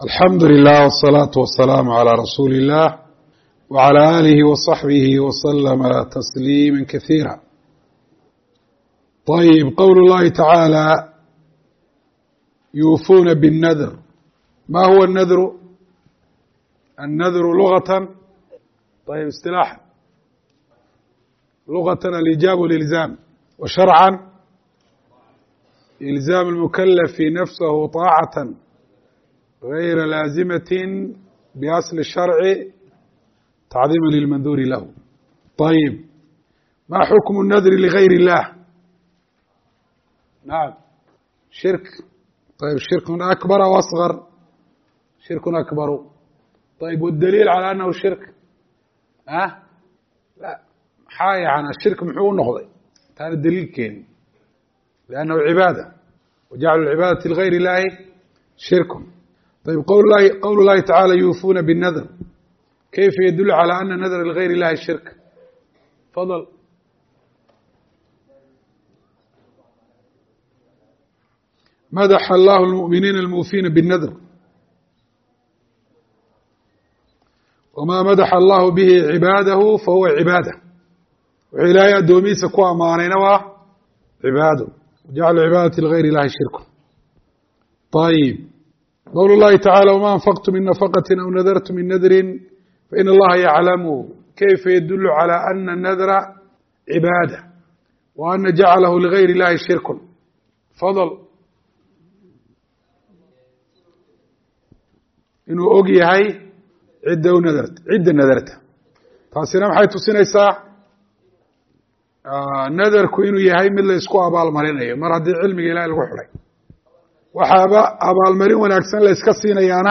Dawratu Ta-siiliyah Ee Masjidu Rashiid Hargeisa